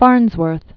(färnzwûrth), Philo Taylor 1906-1971.